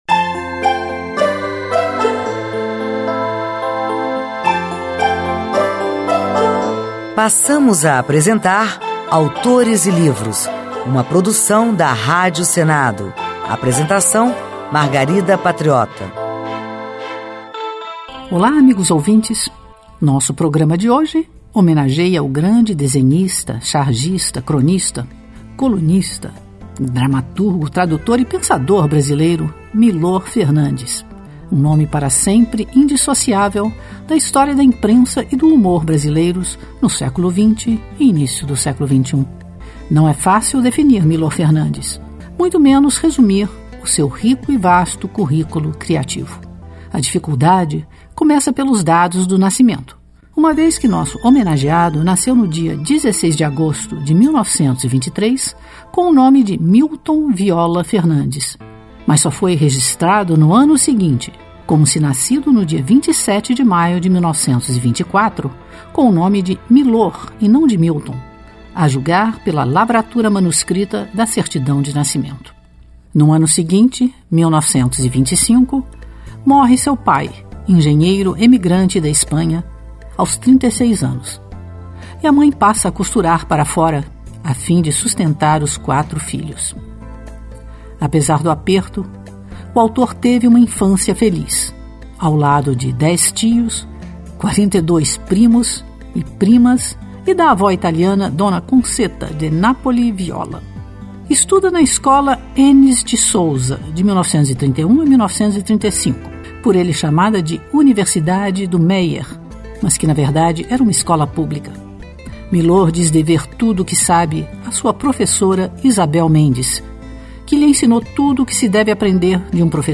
Revista literária com entrevistas com autores, poesias, dicas de livros e também notícias sobre o mundo da literatura e as últimas publicações do Senado Federal